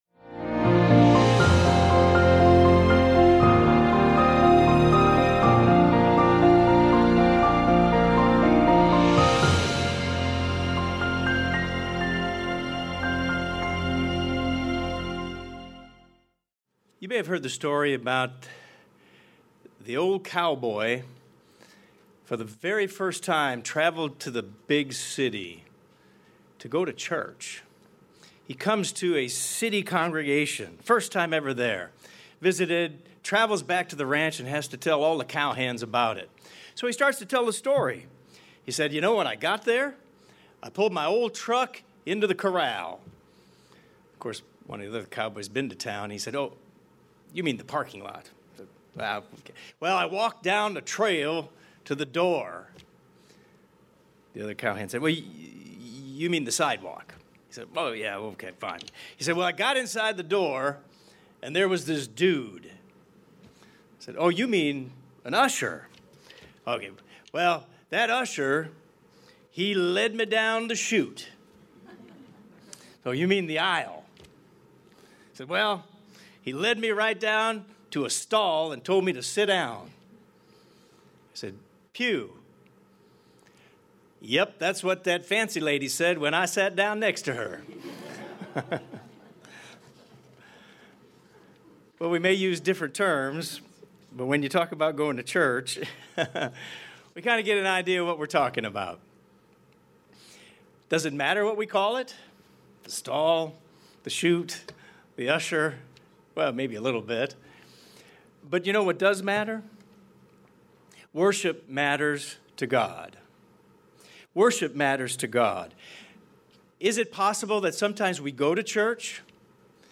This sermon addresses the true nature of worship and how to be sure we aren't just going through the motions.